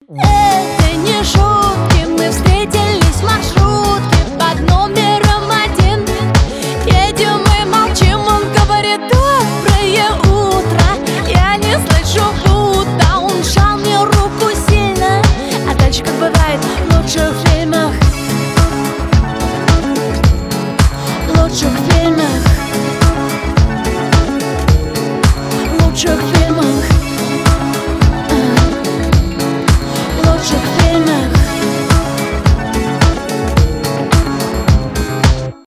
танцевальные , поп